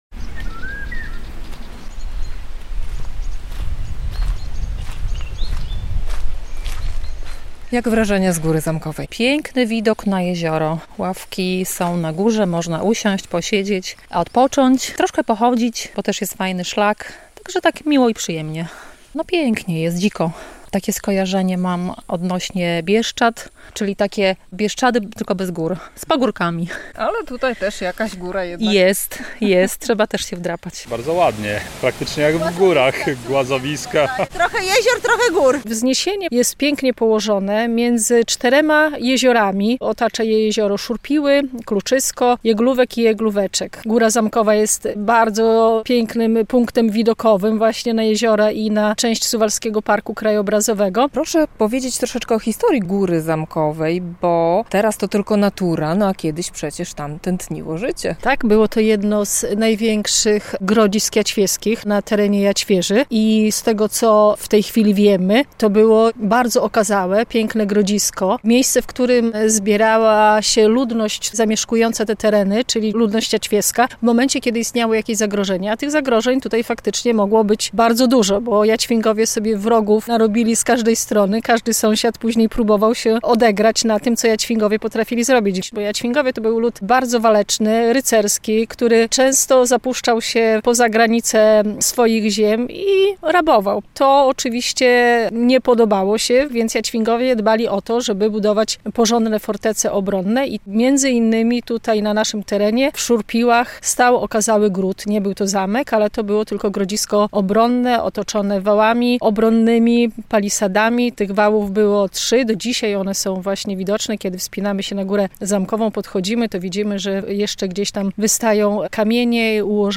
Góra Zamkowa w Szurpiłach - relacja